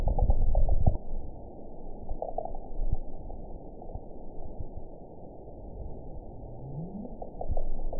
event 921901 date 12/21/24 time 13:09:08 GMT (4 months, 3 weeks ago) score 8.73 location TSS-AB03 detected by nrw target species NRW annotations +NRW Spectrogram: Frequency (kHz) vs. Time (s) audio not available .wav